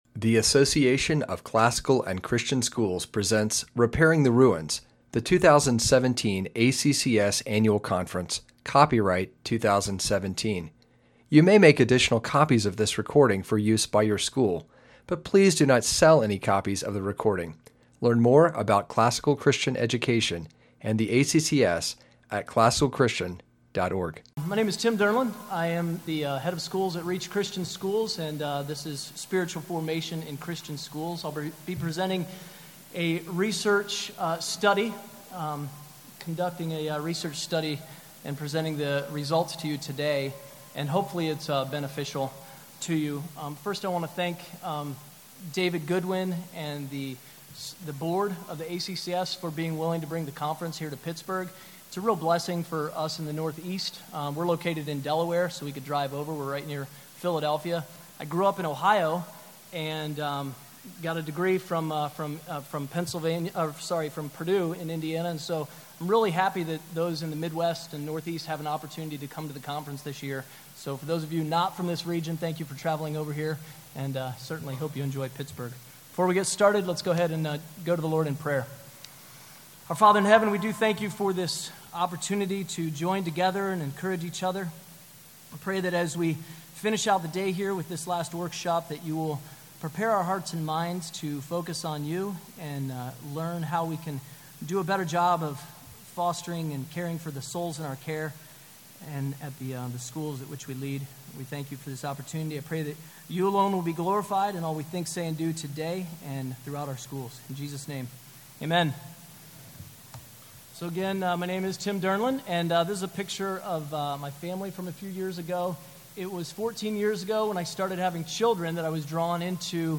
2017 Leaders Day Talk | 0:37:17 | All Grade Levels, General Classroom, Virtue, Character, Discipline
Practical information will be presented on how to advance a robust Christian community in which students can develop a strong commitment to the Christian faith. Speaker Additional Materials The Association of Classical & Christian Schools presents Repairing the Ruins, the ACCS annual conference, copyright ACCS.